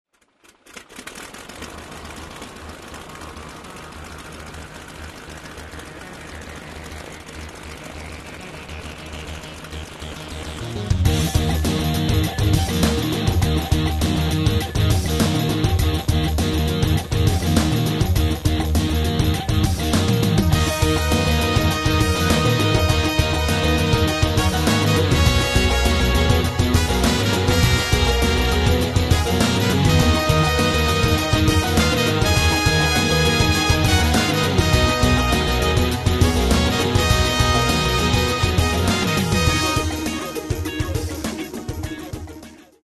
Каталог -> Классическая -> Нео, модерн, авангард
Внутри нее – просторно и легко.